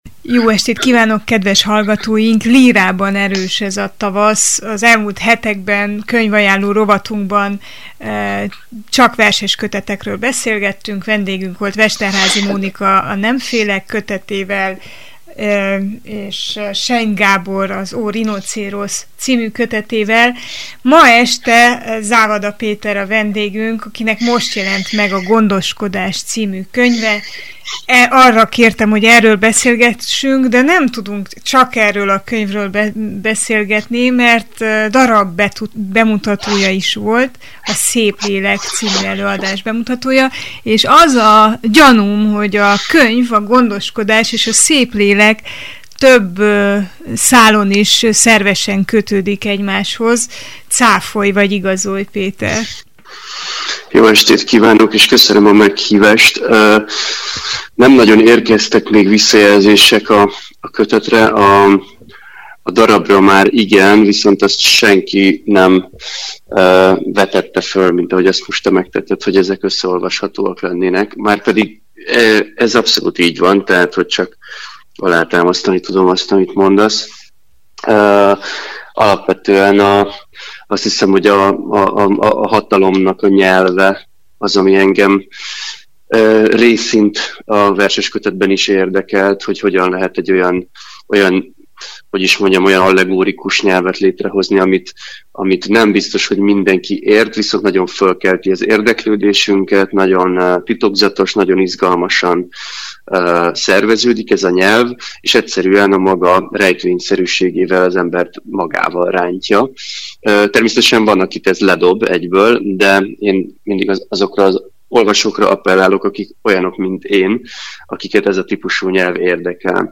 Az új darabról és az új verseskötetről beszélgettünk Závada Péterrel.